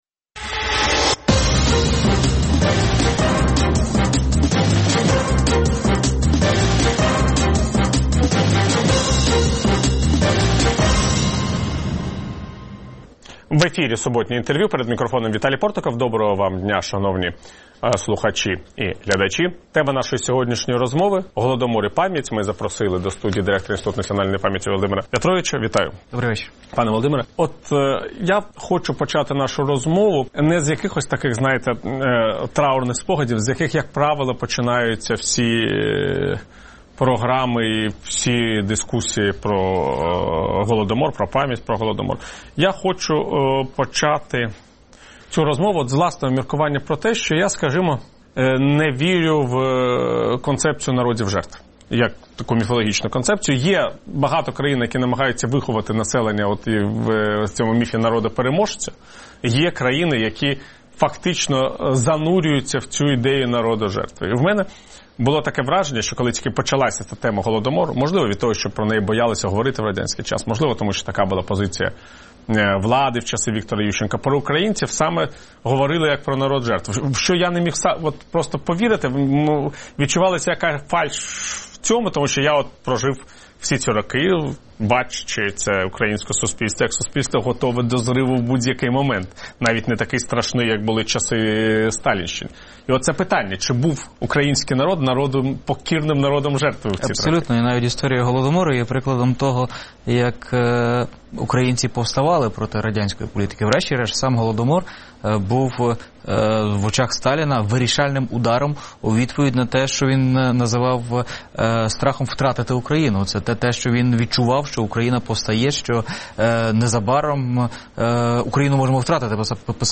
Суботнє інтерв’ю | Голодомор і пам'ять
Гість студії: Володимир В’ятрович, директор Українського інституту національної пам’яті